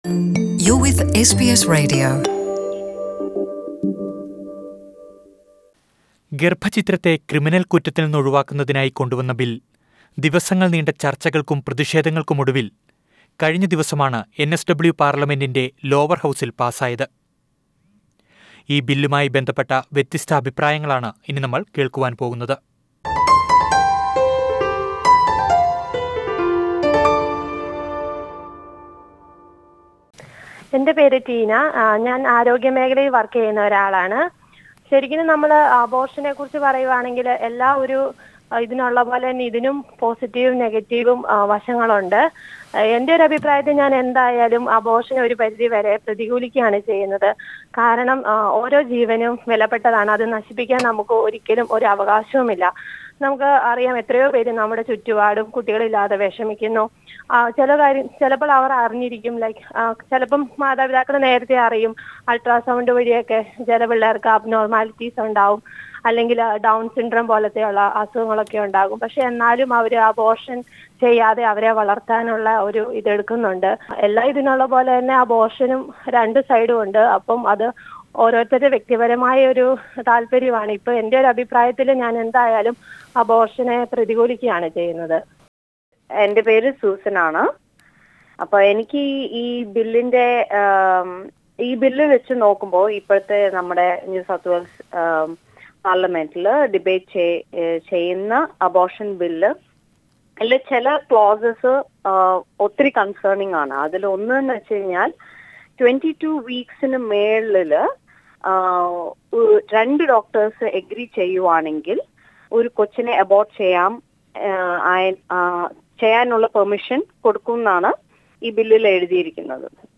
Listen to a few Malayalees from NSW who share their opinions with SBS Malayalam.